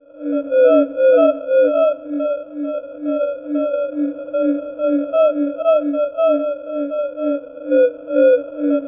CRICKET2+3.wav